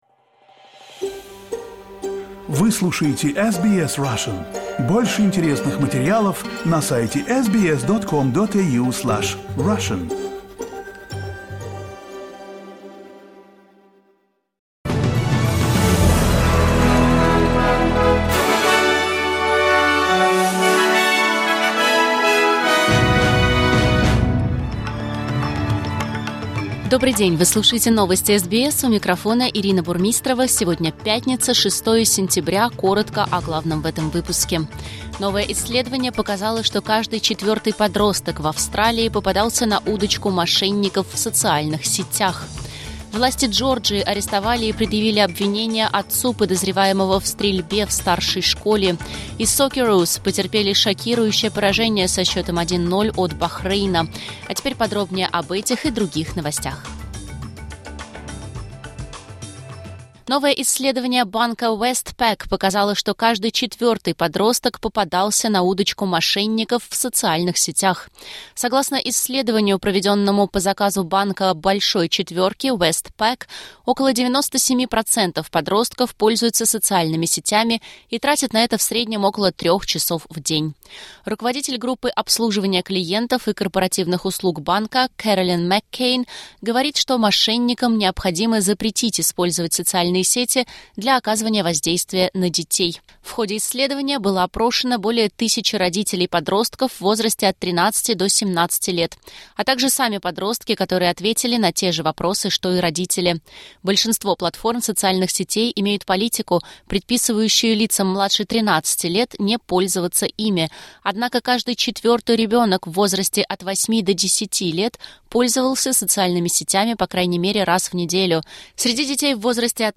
Новости SBS на русском языке — 06.09.2024